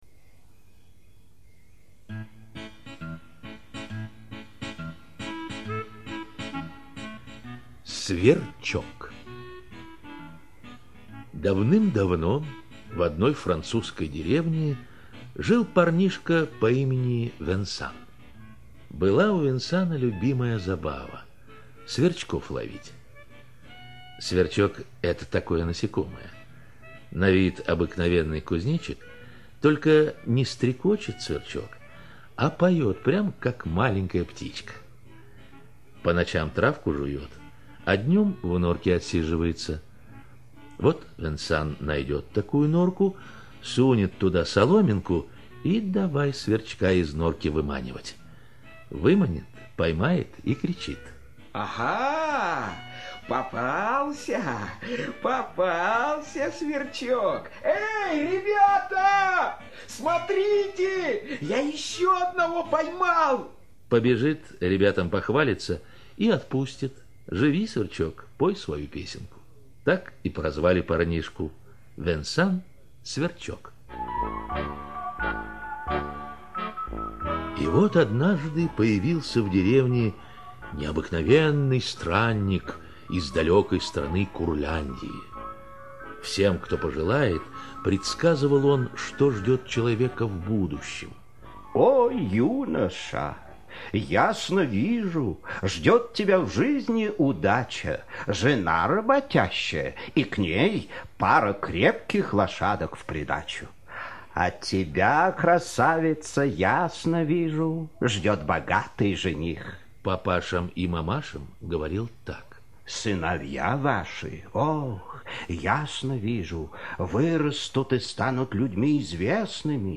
Сверчок - французская аудиосказка - слушать онлайн